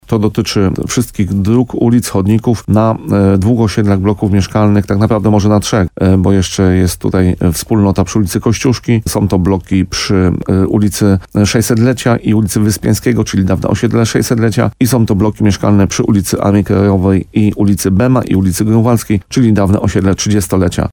Burmistrz Paweł Fyda przyznaje, że to długo oczekiwana przez mieszkańców inwestycja.
– Jesteśmy już po pozytywnym rozstrzygnięciu przetargów – mówił w radiu RDN Nowy Sącz.